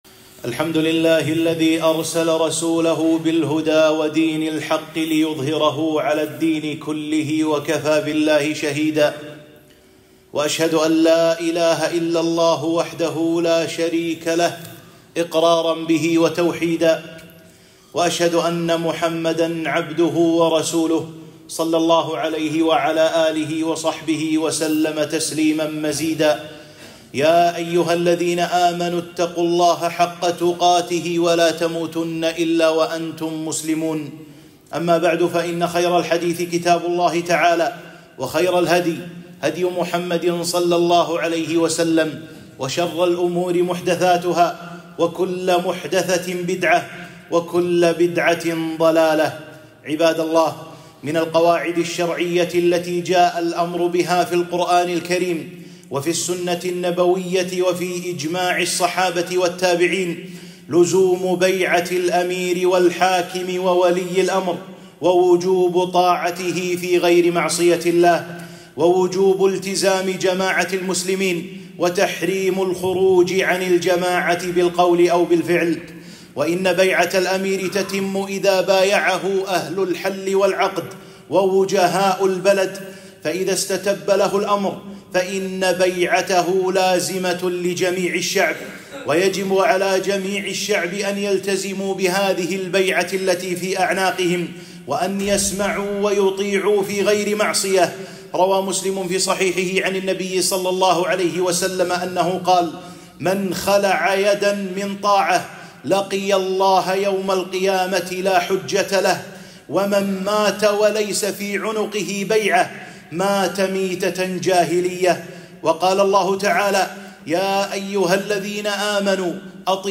خطبة - البيعة الشرعية، طاعة ولي الأمر